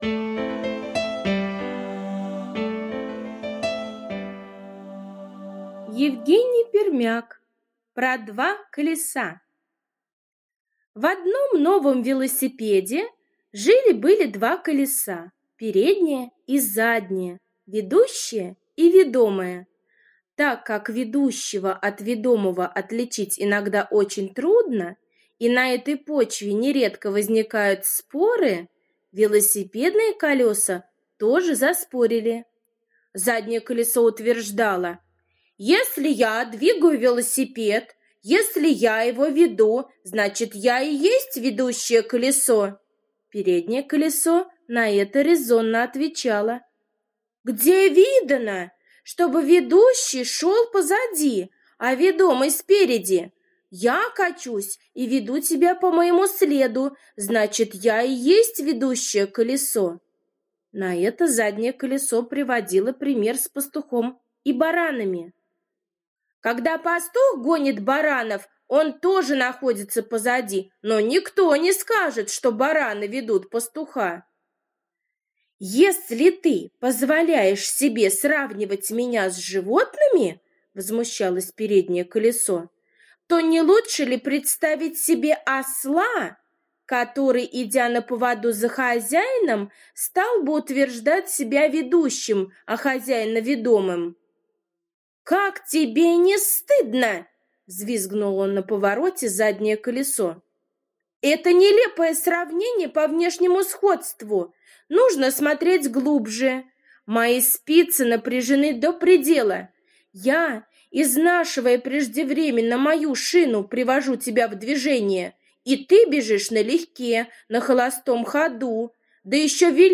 Сказка про два велосипедных колеса, которые все время спорили: кто из них ведущее колесо, а кто ведомое. Аудиосказка Пермяка.